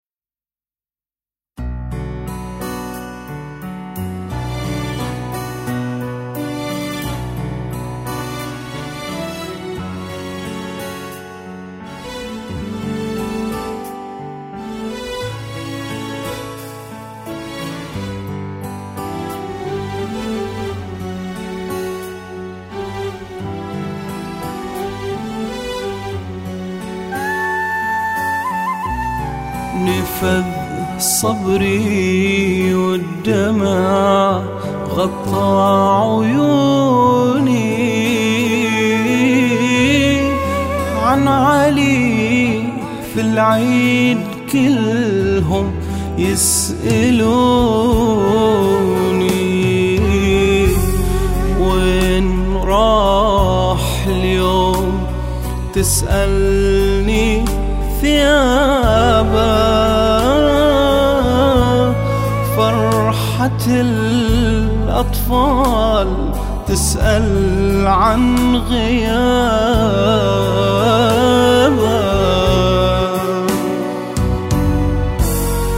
أناشيد بحرينية انشودة وطنية